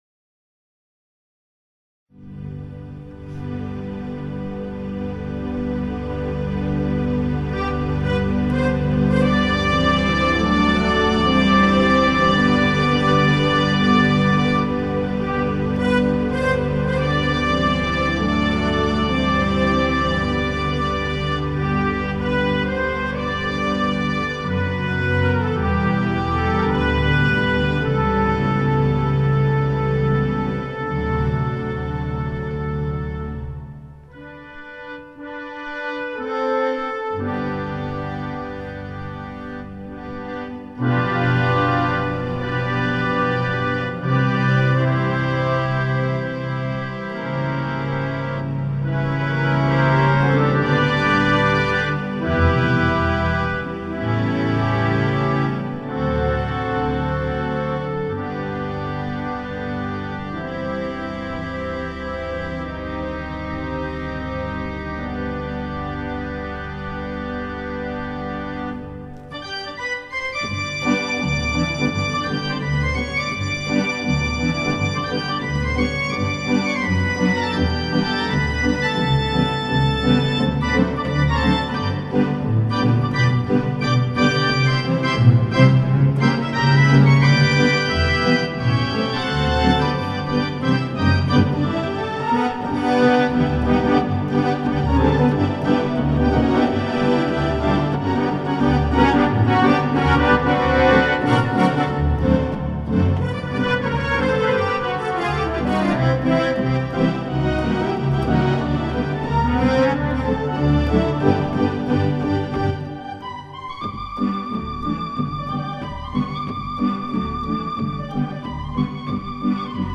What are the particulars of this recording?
In some of the tracks, listed below, you can hear cups being sat down on the table. Also, since this is totally unrehearsed music, there are a number of mistakes, but hey, it's live and impromptue.